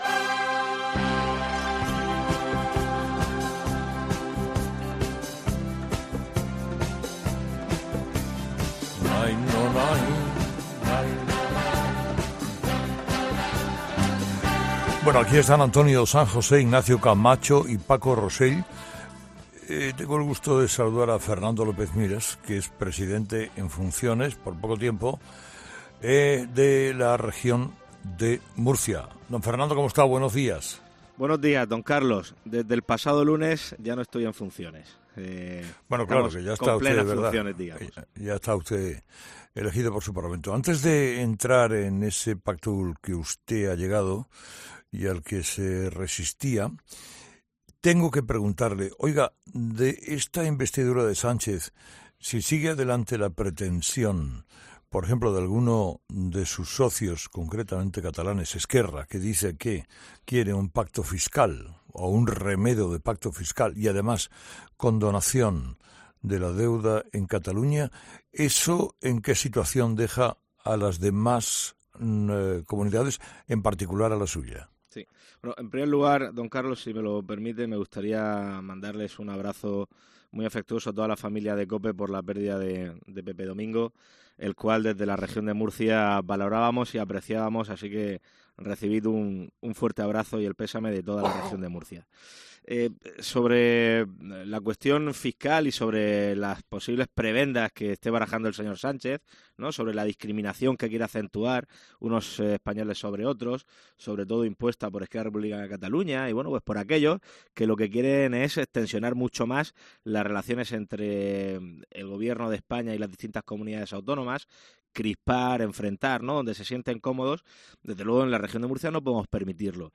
AUDIO: El presidente de la Región de Murcia ha estado este lunes con Carlos Herrera en COPE